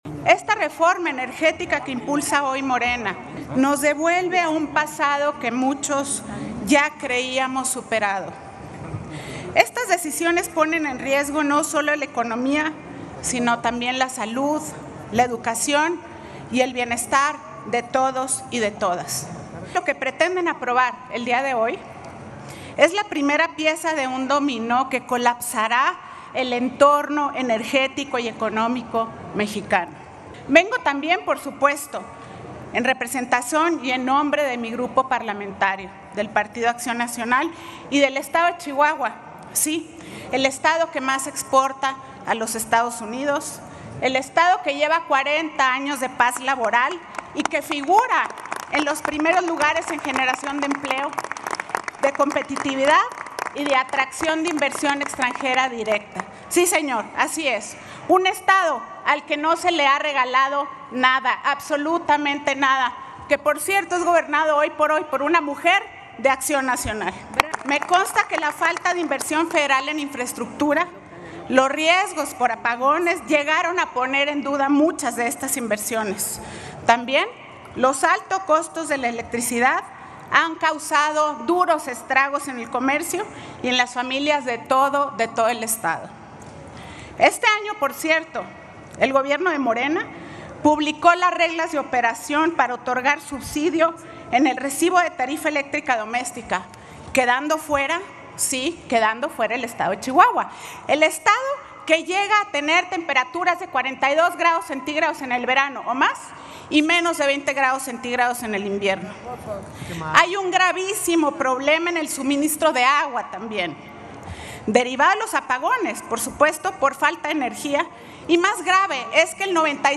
MENSAJE DE MANQUE GRANADOS